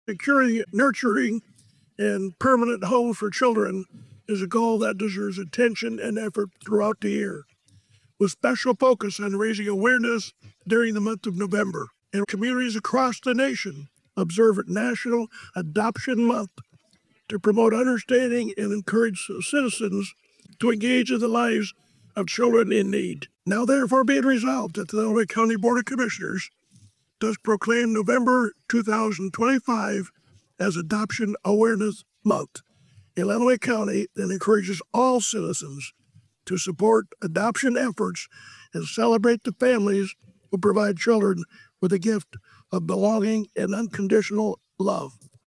Commissioner Terry Collins presented the proclamation, emphasizing that securing safe and nurturing homes is a year-round priority, with National Adoption Month serving as a focused reminder of that work.
That was Commissioner Terry Collins.